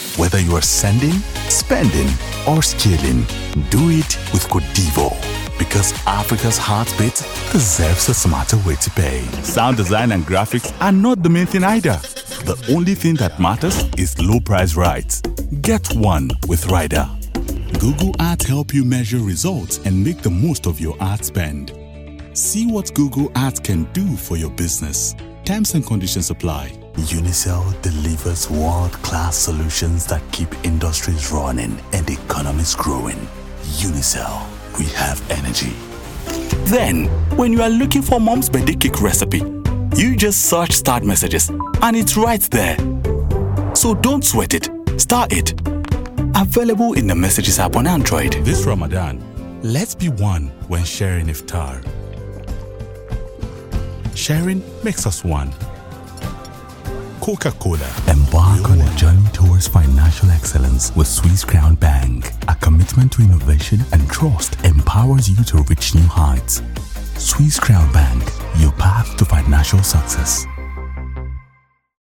English (African)
Natural
Warm
Gravitas